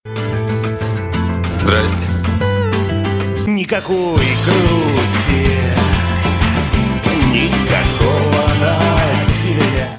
• Качество: 128, Stereo
позитивные